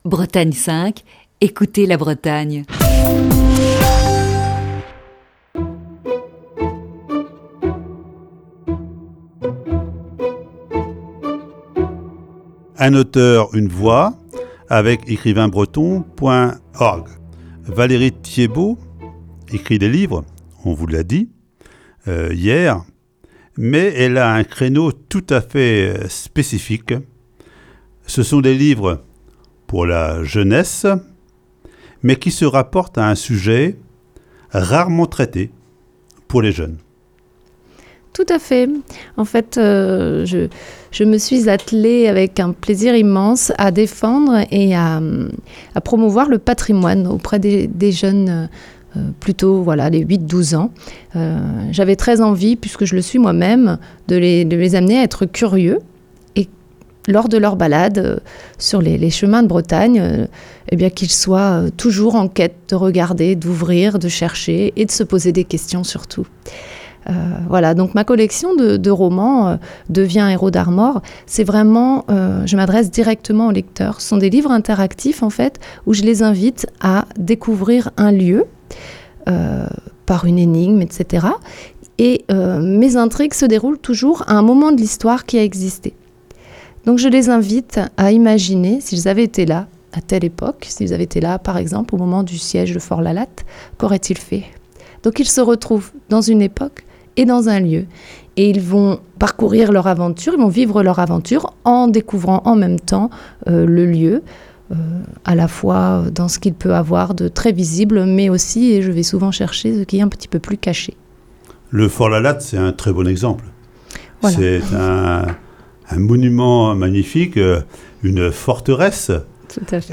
Voici ce jeudi, la quatrième partie de cet entretien.